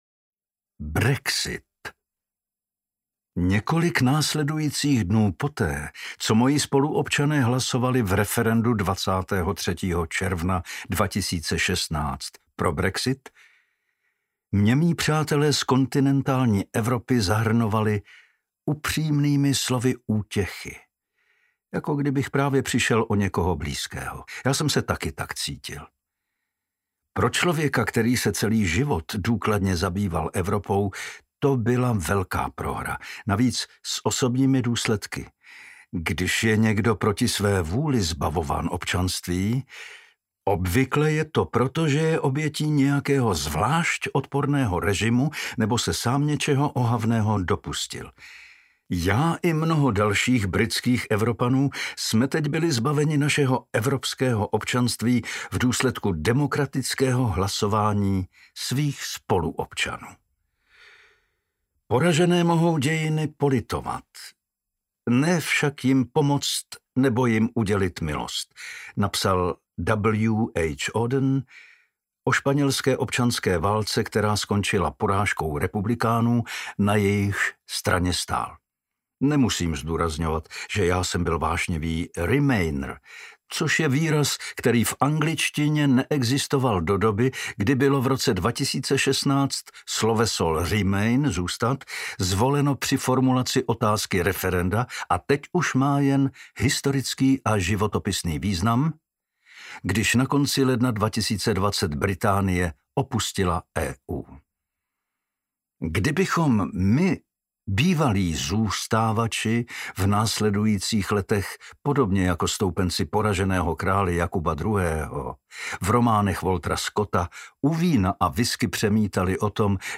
Audioknihu můžete také darovat
Čte: Pavel Soukup